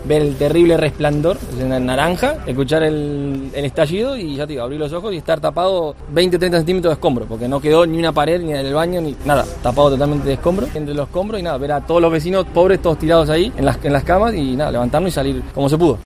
Vecino del edificio de Lardero tras la explosión de gas: Abrí los ojos tapado con 30 centímetros de escombro